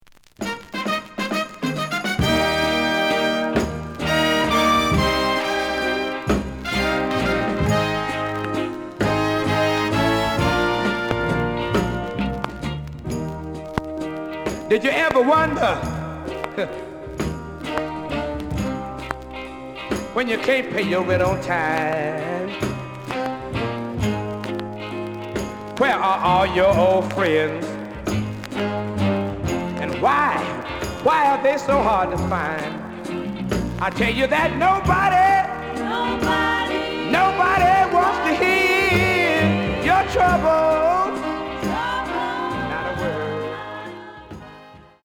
The audio sample is recorded from the actual item.
●Genre: Soul, 60's Soul
Some click noise on A side due to scratches.